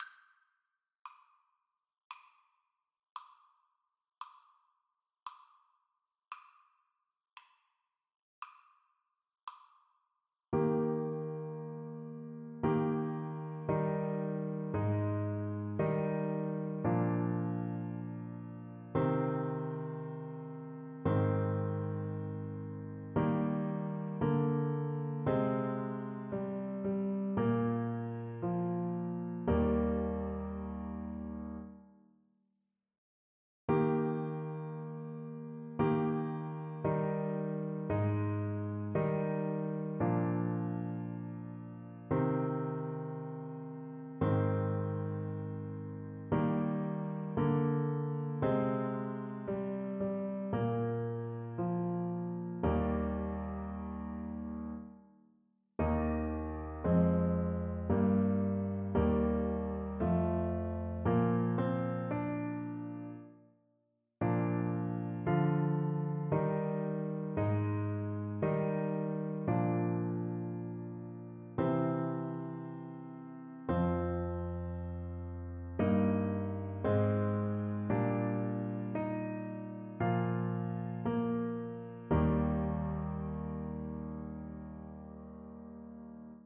Trumpet
Traditional Music of unknown author.
Eb major (Sounding Pitch) F major (Trumpet in Bb) (View more Eb major Music for Trumpet )
4/4 (View more 4/4 Music)
Christmas (View more Christmas Trumpet Music)